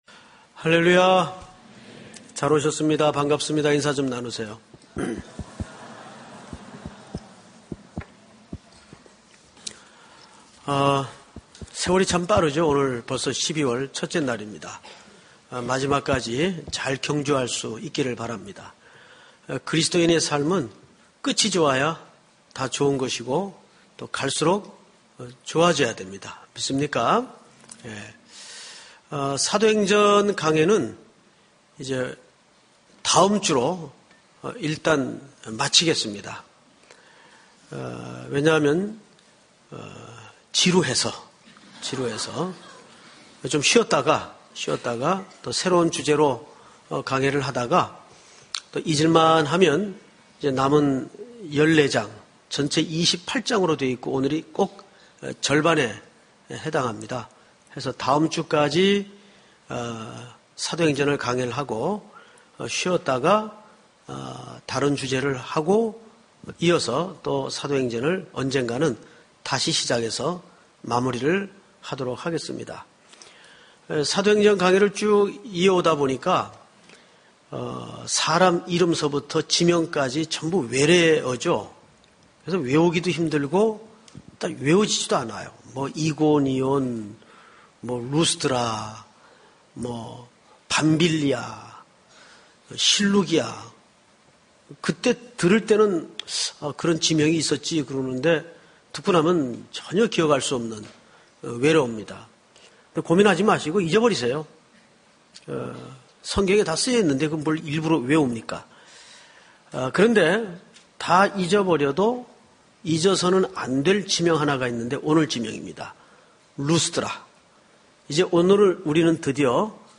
사도행전 강해(24)
주일예배